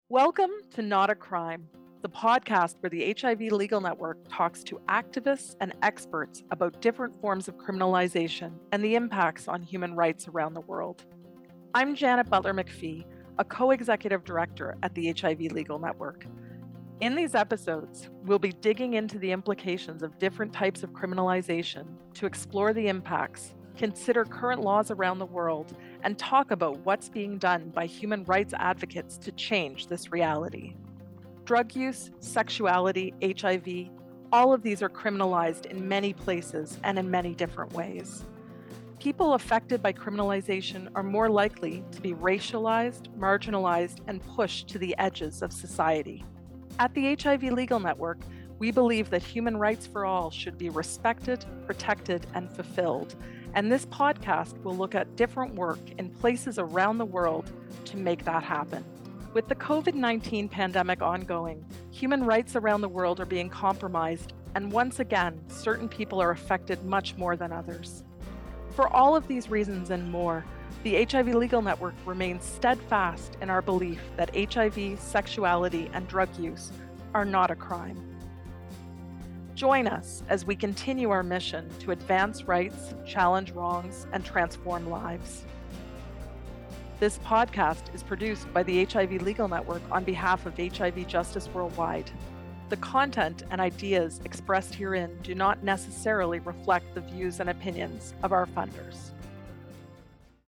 Not-a-Crime-Podcast-Intro.mp3